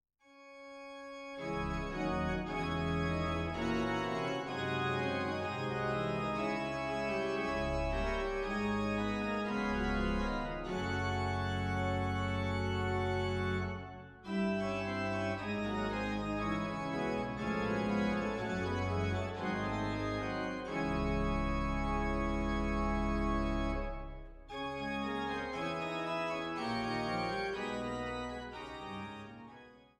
Choralbearbeitung